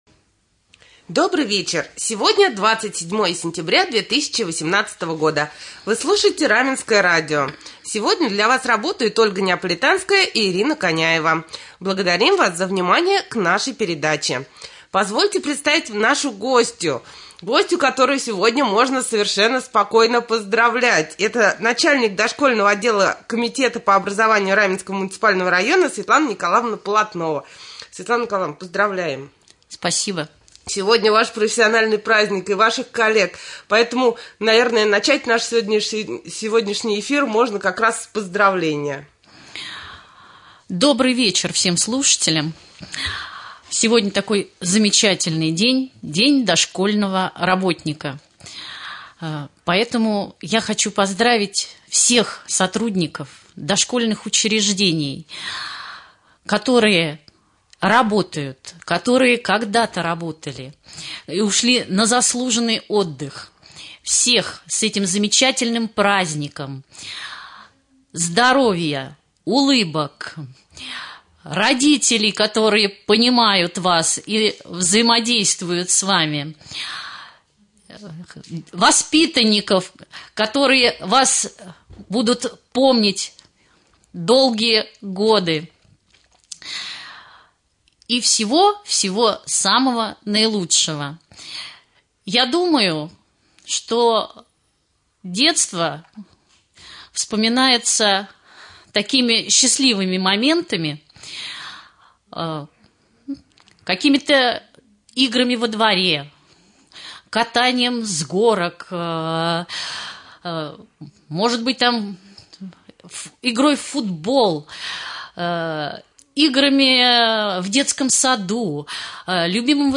Гость студии